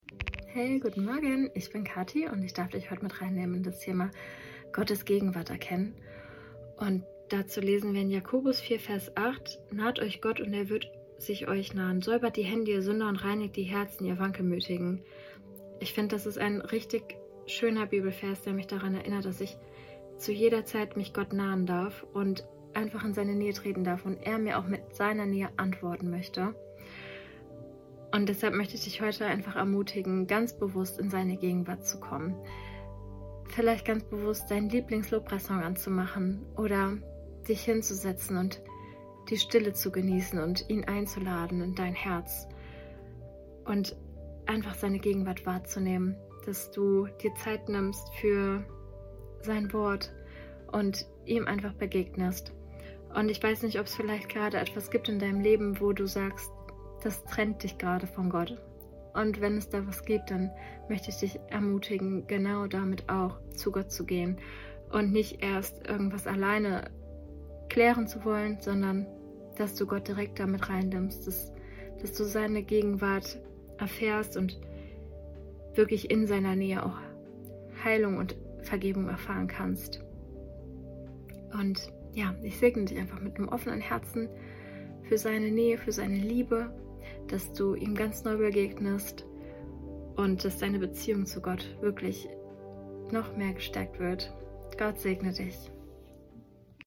Tag 6 der Andacht zu unseren 21 Tagen Fasten & Gebet